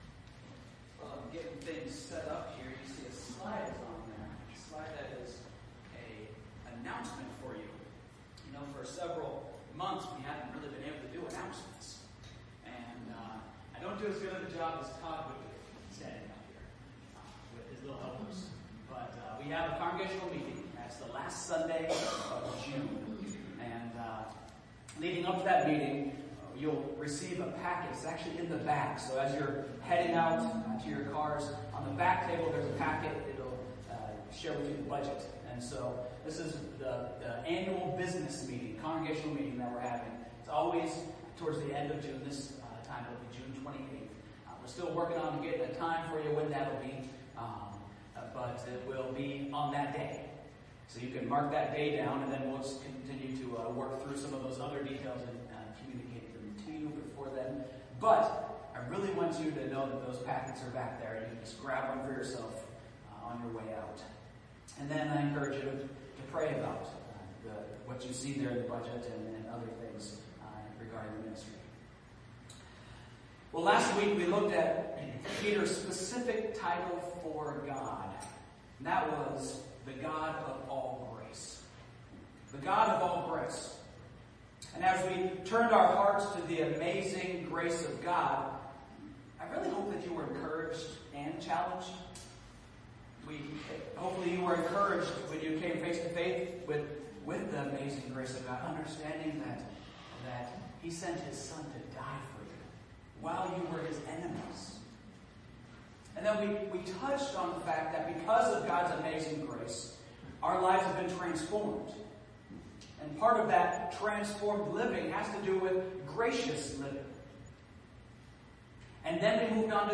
If you were unable to join us, we hope that you enjoy a recording of the 9 a.m. service.